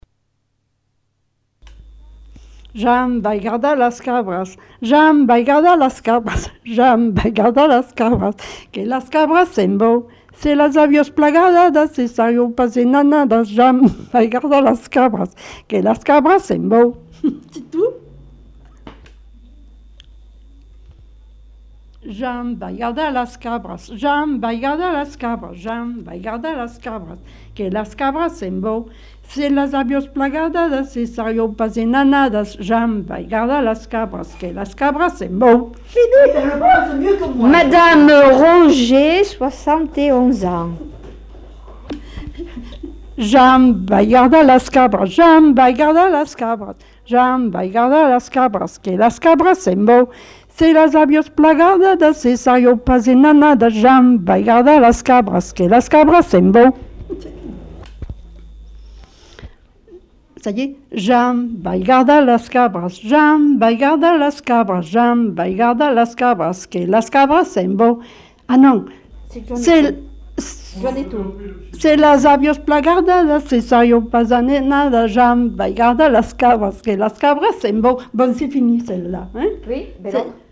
Lieu : Lodève
Genre : chant
Effectif : 1
Type de voix : voix de femme
Production du son : chanté
Classification : enfantines diverses